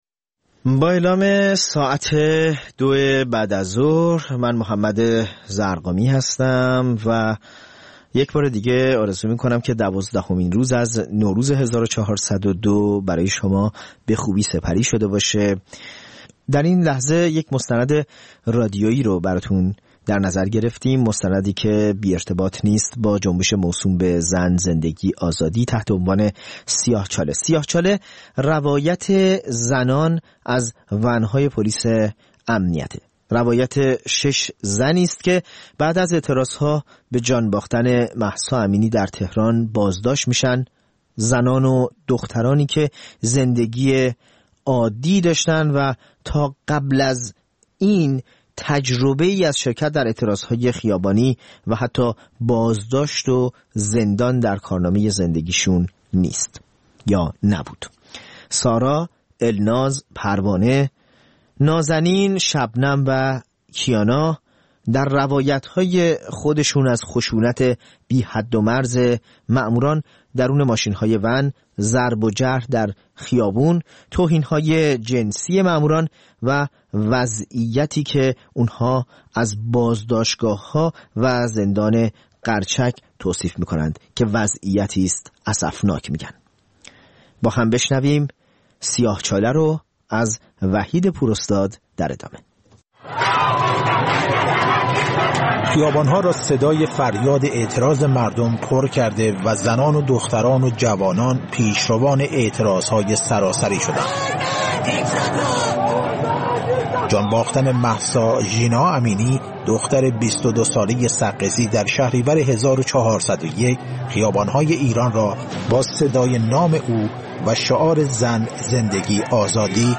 مستند رادیویی: سیاه‌چاله؛ روایت زنان از و‌ن‌های پلیس